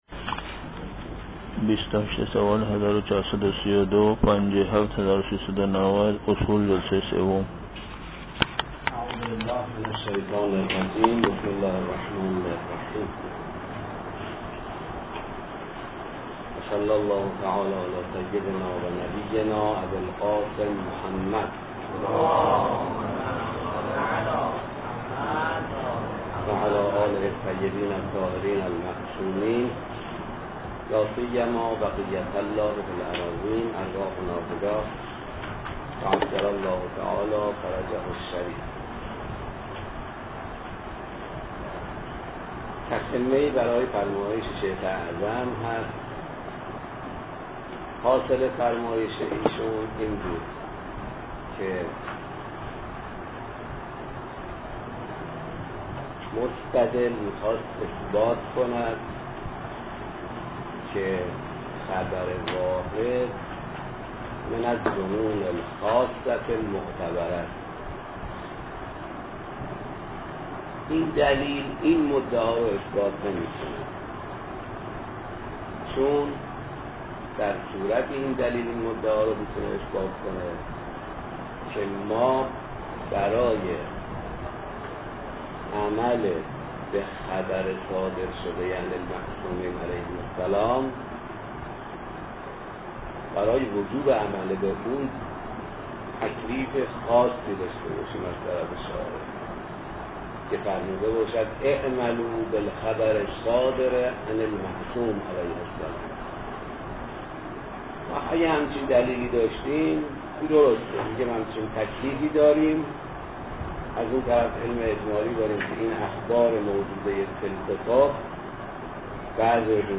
پخش آنلاین درس